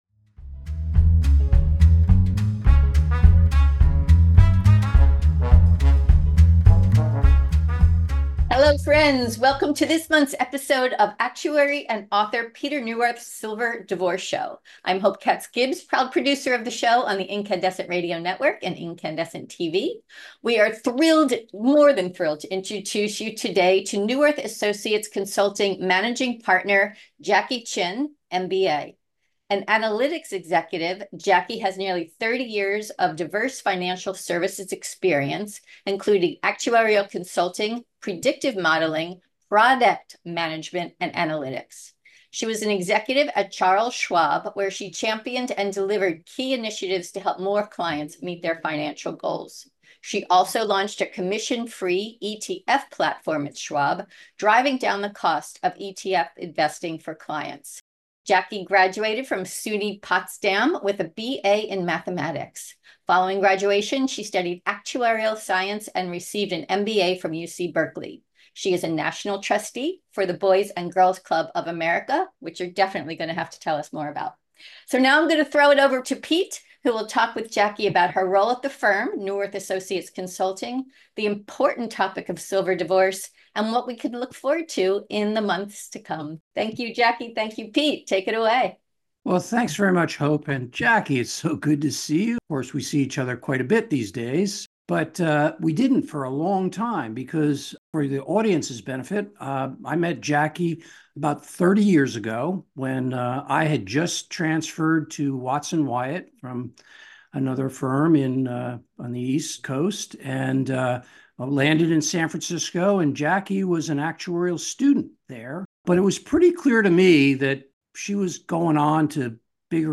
Meet our guest